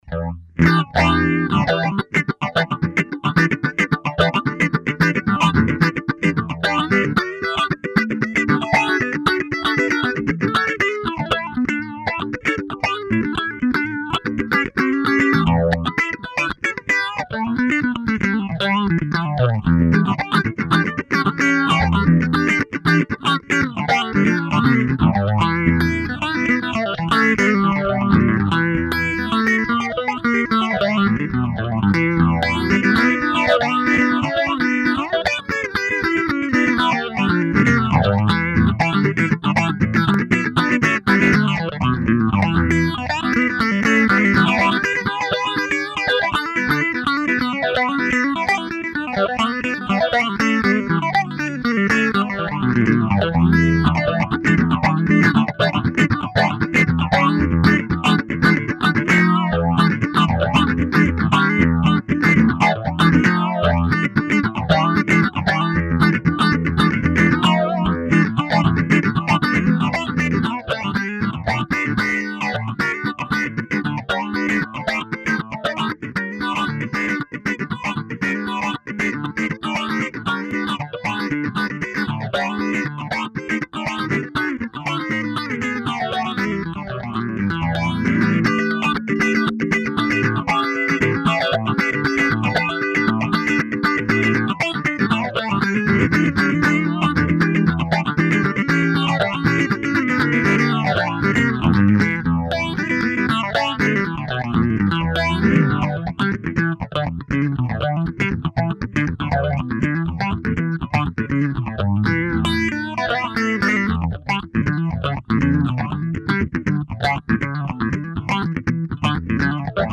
Analog pedal guitar effect from the seventies. It is basically a phaser with envelope follower on the filter
info SOUND basically the polyphase is a 6-stages phaser working with 2 particular main modes:
First mode uses the internal LFO for a really sweepy and marvelous spatial sound very harmonic and a present EQ, and best of all, it doe not eat up all the sound bandwidth. The Envelope EG mode is gated by guitar peak and retrig the amount of phasing.
demo guitar demo 1
REVIEW "THE Original EHX sound. Raw and full harmonics sound without any digital frills."